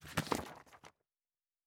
pgs/Assets/Audio/Fantasy Interface Sounds/Book 03.wav at master